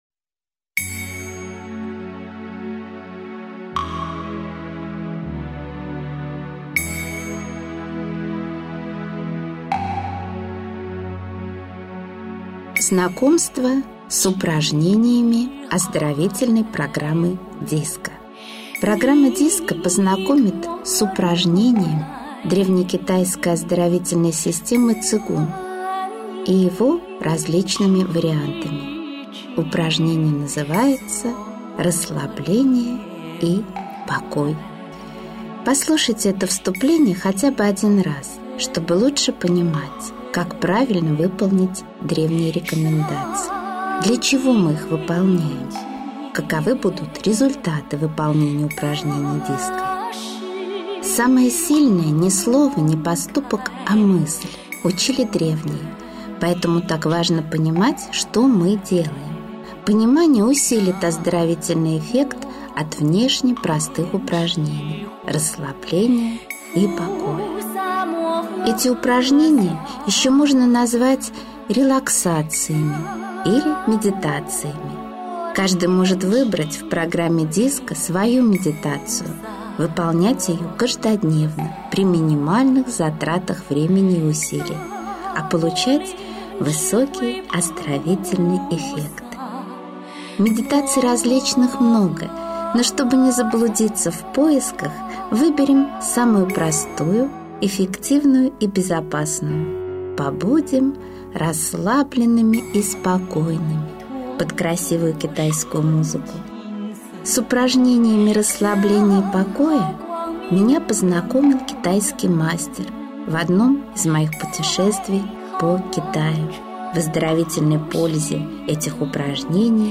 Аудиокнига Цигун. Медитация. Расслабление и покой | Библиотека аудиокниг
Прослушать и бесплатно скачать фрагмент аудиокниги